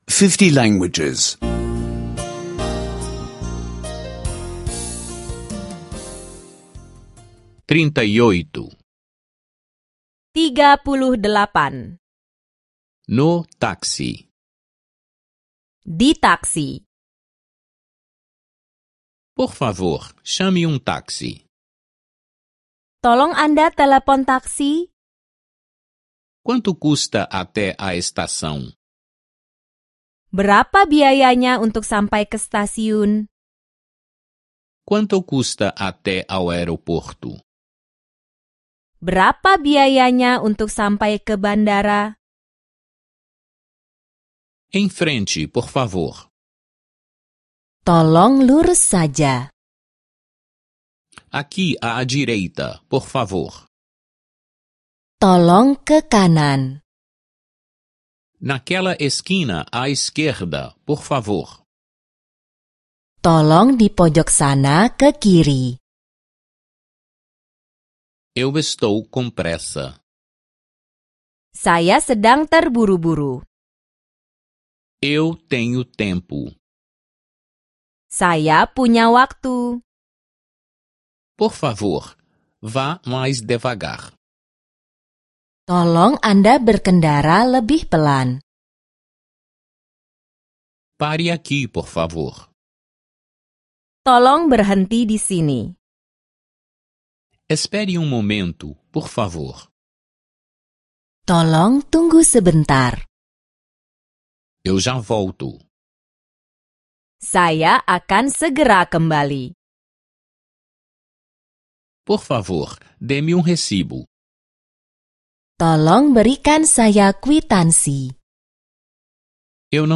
Aulas de indonésio em áudio — escute online